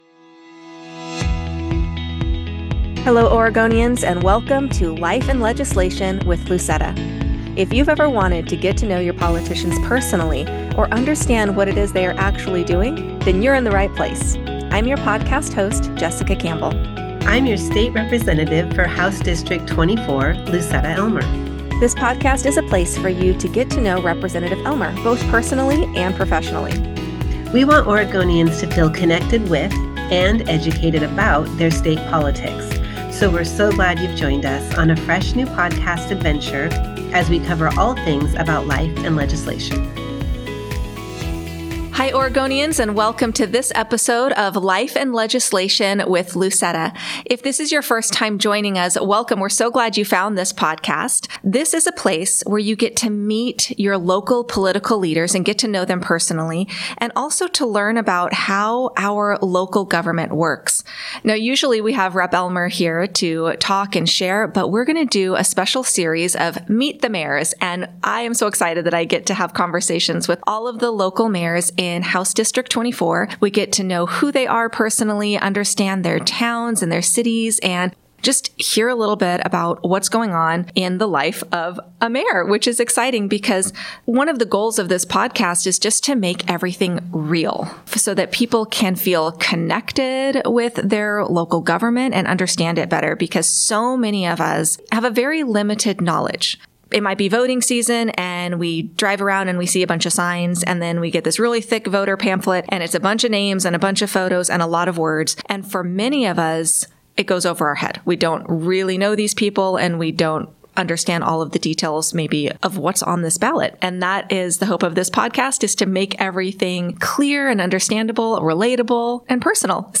In this episode, we begin our "Meet the Mayors" series and welcome Mayor King to the podcast.